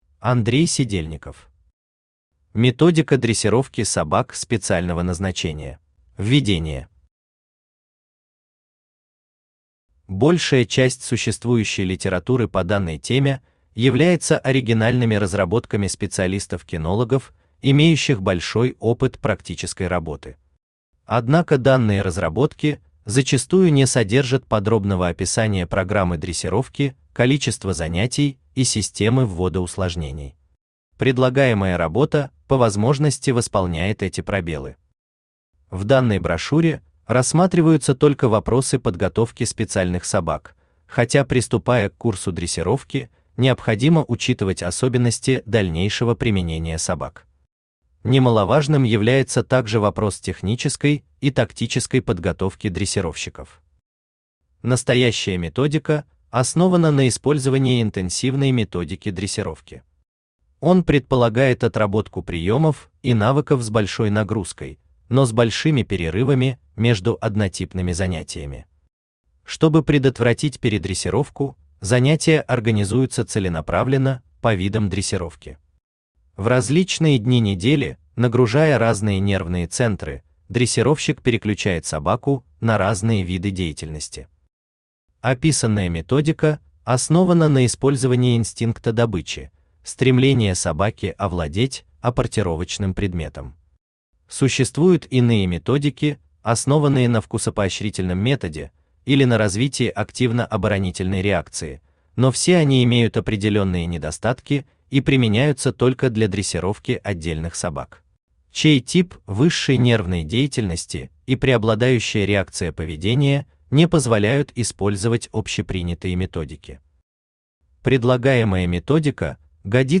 Аудиокнига Методика дрессировки собак специального назначения | Библиотека аудиокниг
Aудиокнига Методика дрессировки собак специального назначения Автор Андрей Сидельников Читает аудиокнигу Авточтец ЛитРес.